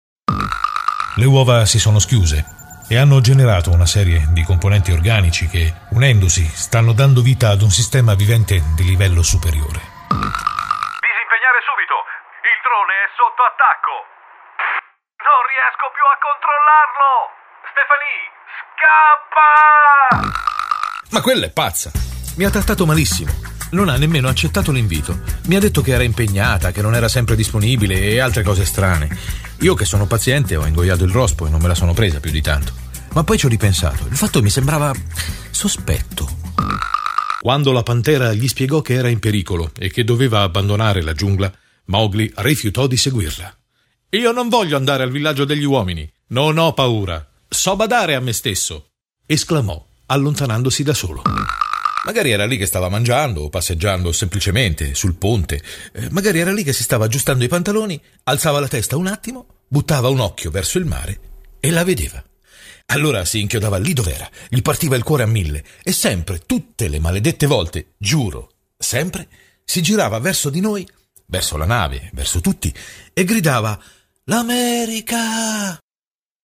una voce maschile versatile: calda e profonda o dinamica e piena di energia.
Sprechprobe: Sonstiges (Muttersprache):
versatile Voice: warm and deep or dynamic and full of energy.